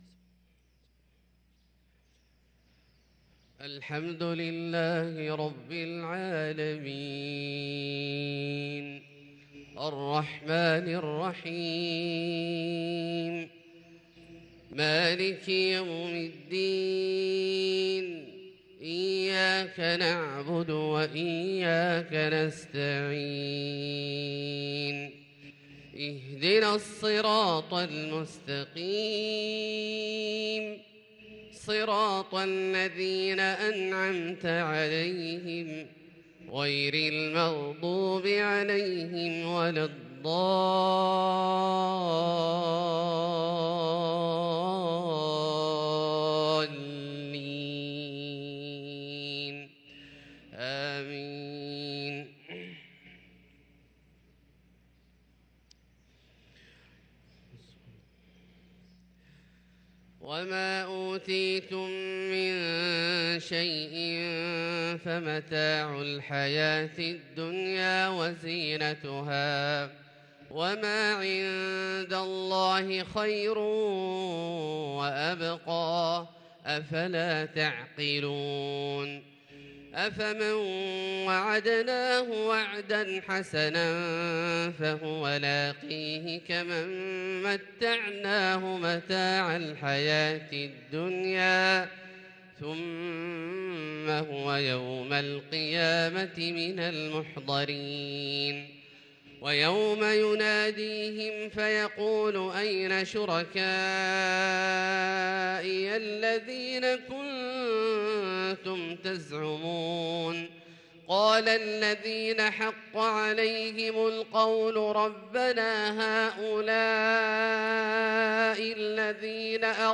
صلاة الفجر للقارئ عبدالله الجهني 15 صفر 1444 هـ
تِلَاوَات الْحَرَمَيْن .